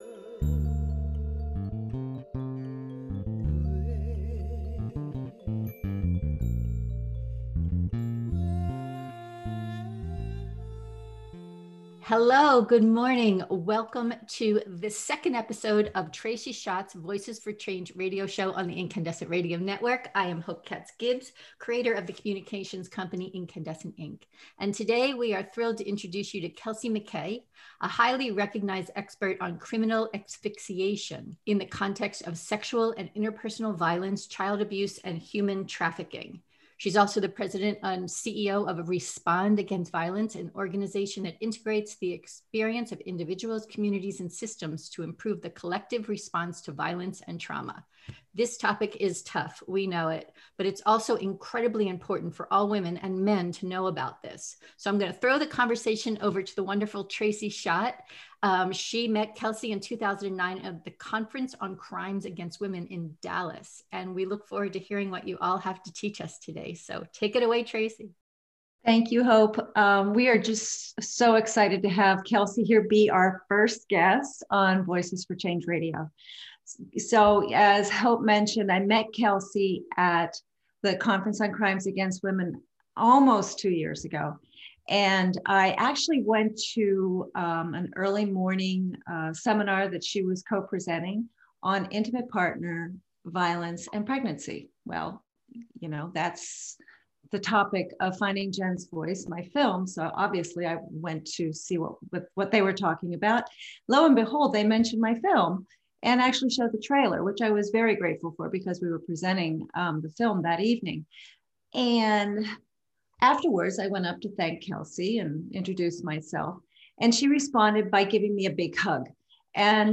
In this interview: